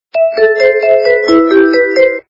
Звук для СМС - Колокольчики Звук Звуки Звук для СМС - Дзвіночки
При прослушивании Звук для СМС - Колокольчики качество понижено и присутствуют гудки.